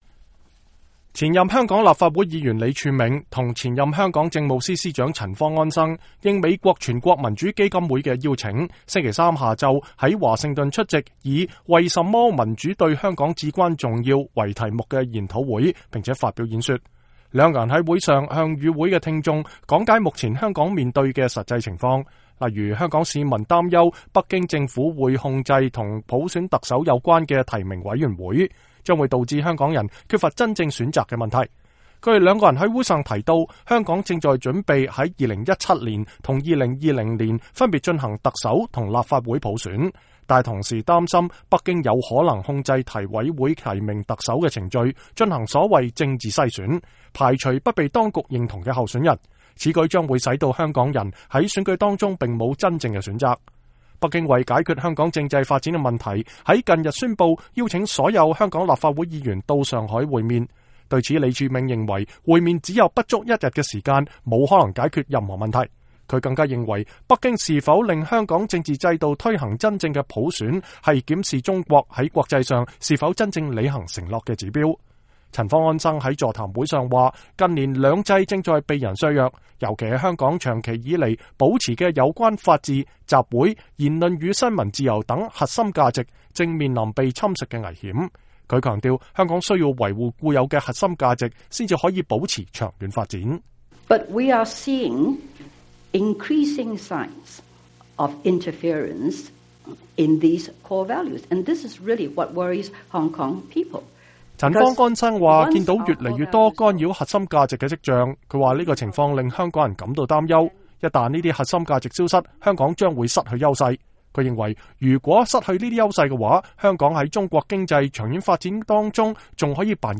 兩名香港泛民主派的重量級政界人士在這個星期到訪華盛頓，就香港政治制度實行全民普選的問題在華盛頓發表公開演說。